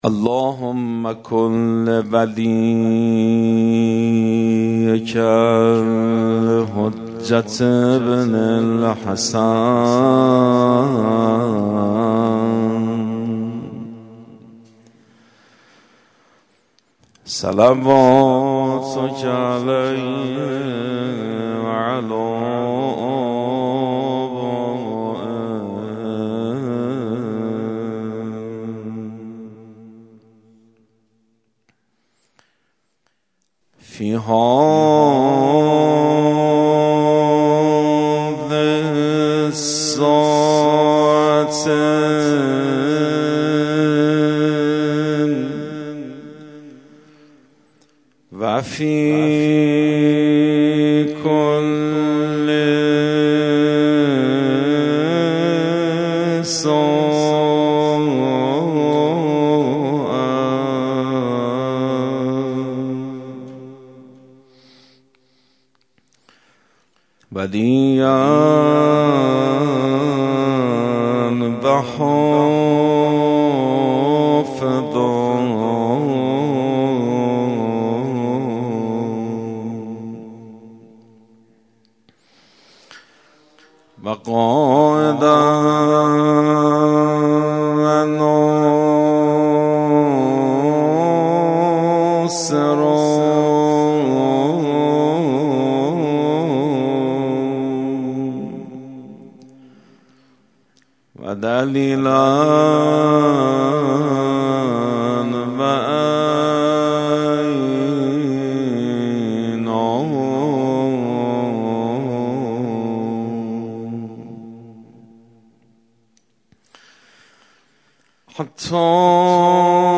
خیمه گاه - روضةالشهداء - شعر مناجاتی
احیا شب اول رجب